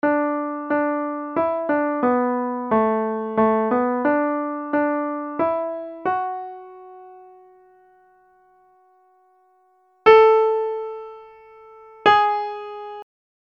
Key written in: D Major
Each recording below is single part only.
Other part 1:
a piano